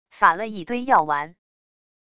撒了一堆药丸wav音频